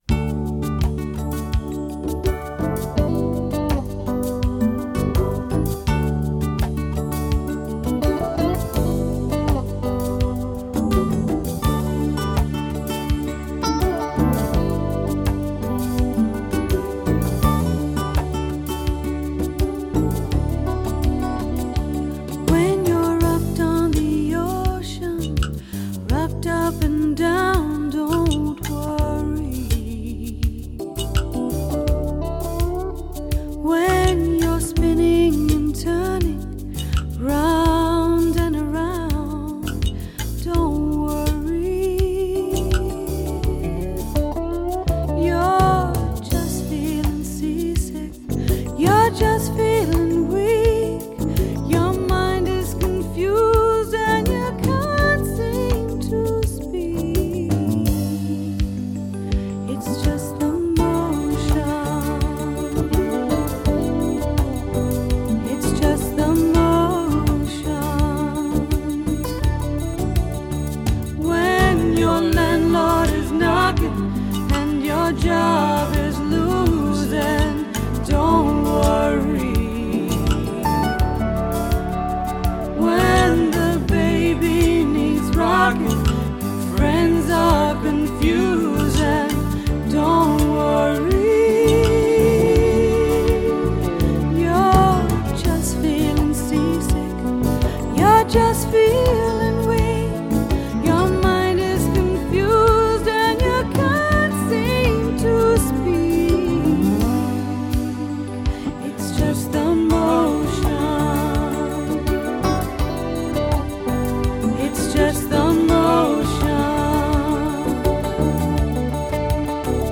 ★1990 年代人手一張的女聲測試片，以原始母帶精心重製，收錄三首原版未收錄之新曲！